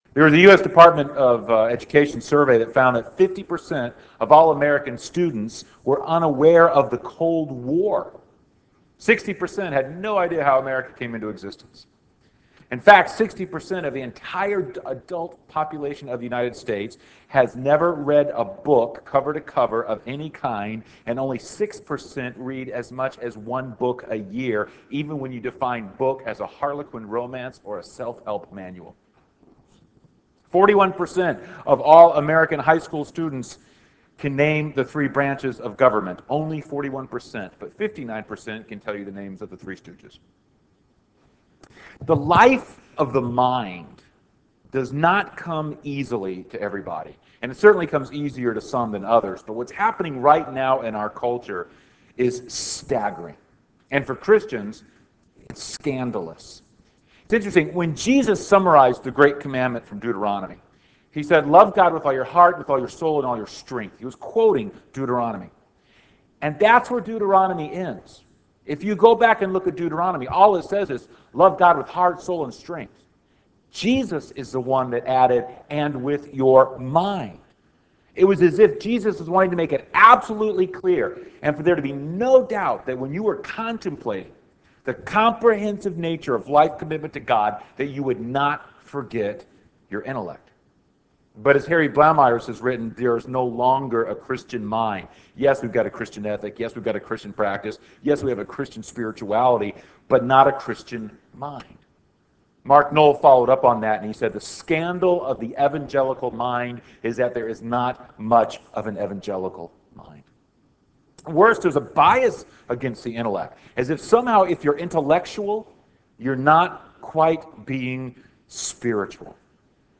N.C. Address: "Developing Our Minds" Recording Date